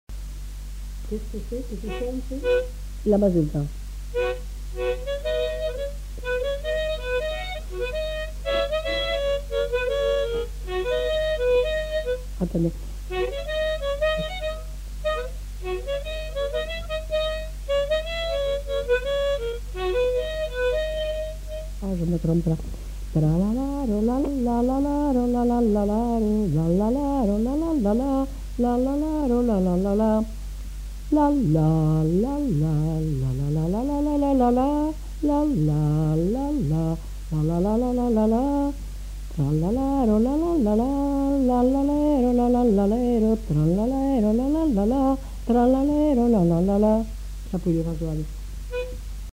Mazurka
Aire culturelle : Haut-Agenais
Lieu : Cancon
Genre : chant
Type de voix : voix de femme
Production du son : fredonné
Instrument de musique : harmonica
Danse : mazurka
Notes consultables : L'informatrice essaie de la jouer à l'harmonica avant de la chanter.